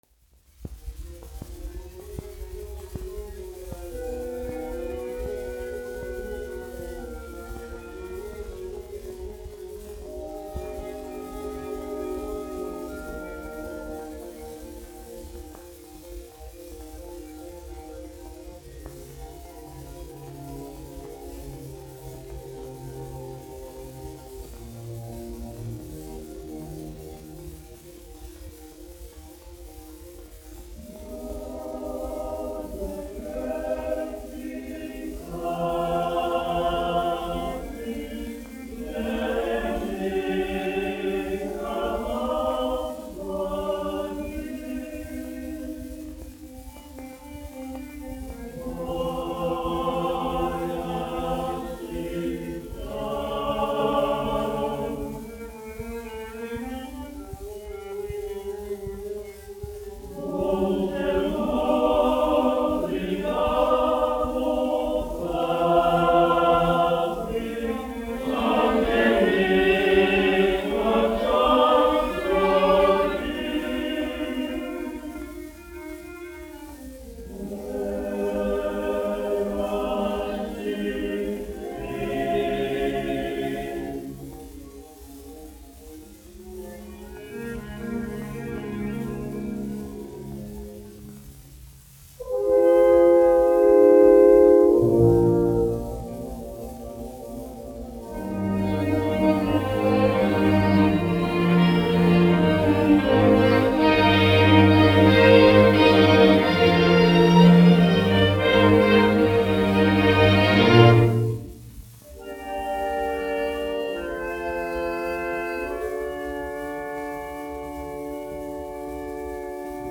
Latvijas Nacionālā opera. Koris, izpildītājs
Emil Cooper, diriģents
1 skpl. : analogs, 78 apgr/min, mono ; 30 cm
Operas--Fragmenti
Skaņuplate